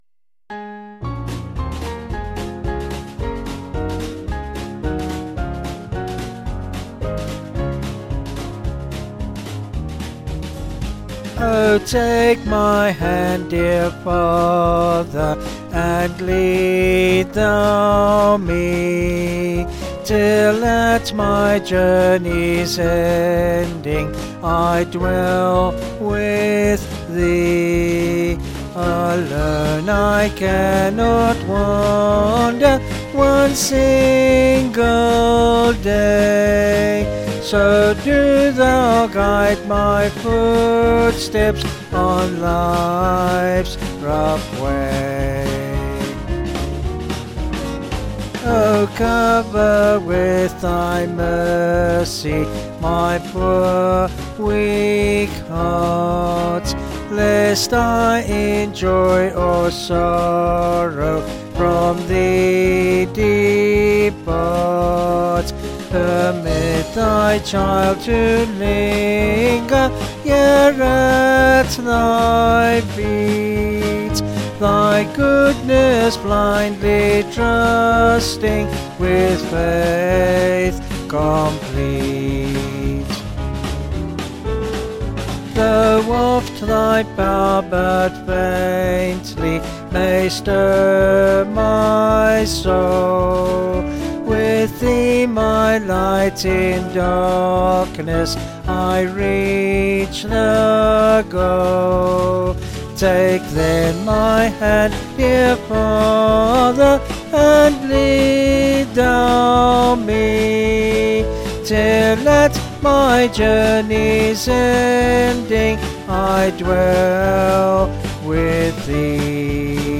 Vocals and Band   263.9kb Sung Lyrics